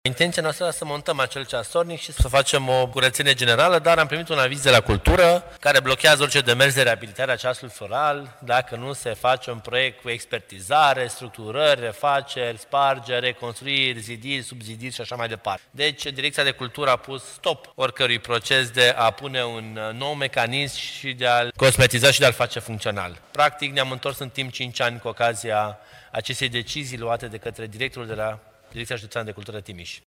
Viceprimarul Ruben Lațcău spune sunt necesare lucrări ample, inclusiv de structură.